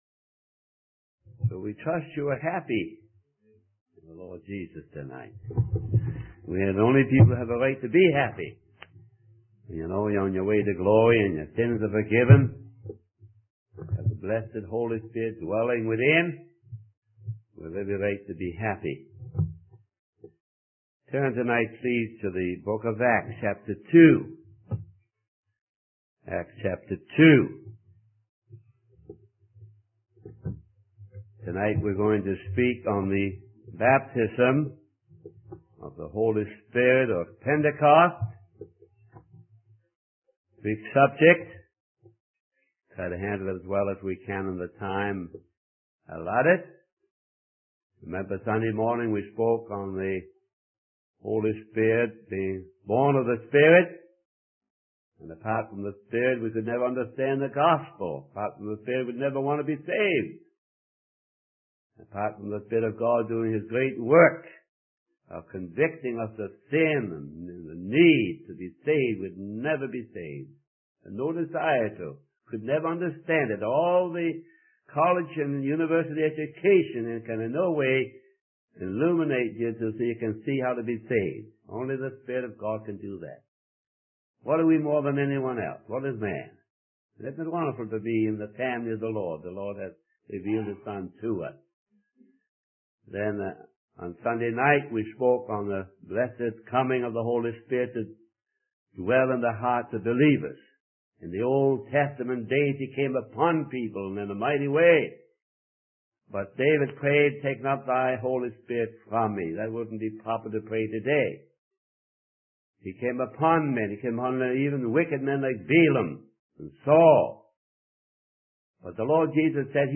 In this sermon, the preacher focuses on Acts chapter 10, where Peter is sent for by Cornelius, an angel that appeared to him.